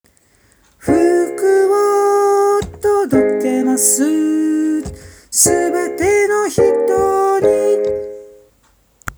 うららかな雰囲気と優しく流れる旋律に癒されます。どこか懐かしさも感じる素敵な作品です。